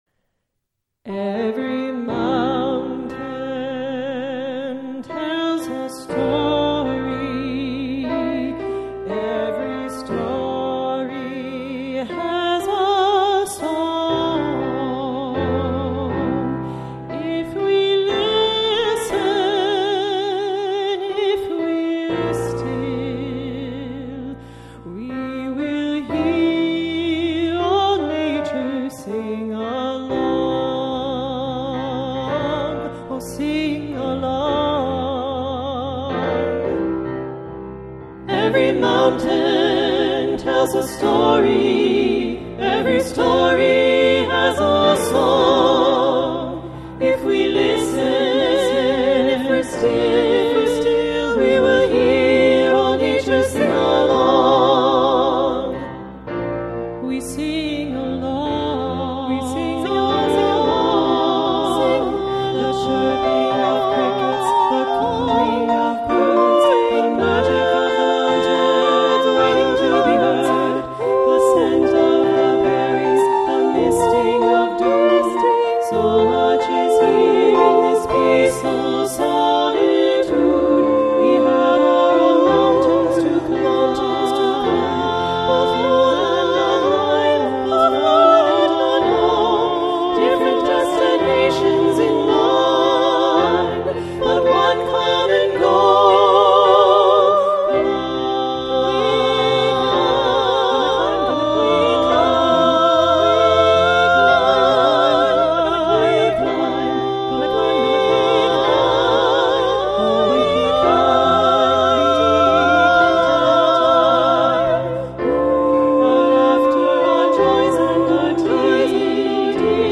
Voicing: SSAA and Piano